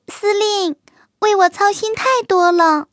卡尔臼炮小破修理语音.OGG